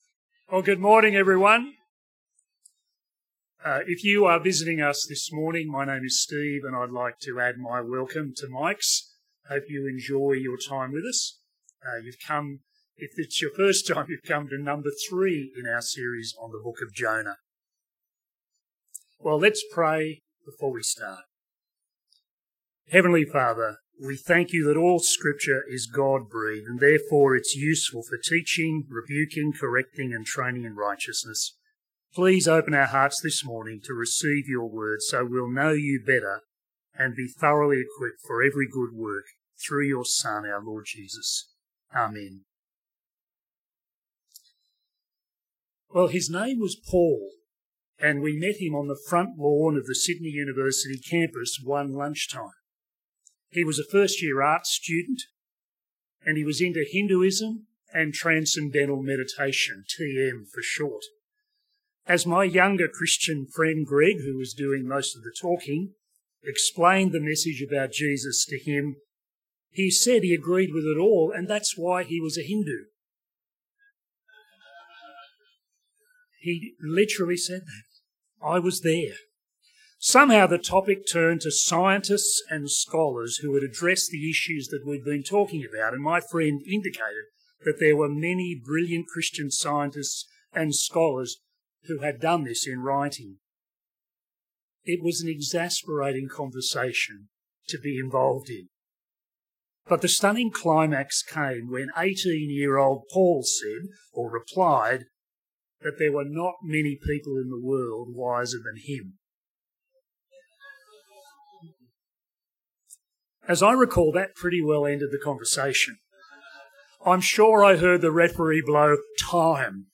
Sermons
Bible talk on the book of Jonah Chapter 3 .